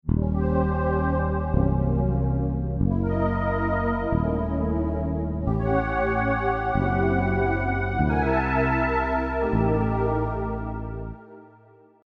Then after a few design iterations, a simple tension-release harmonic scheme developed.
gscrecordingschordswithfretless.mp3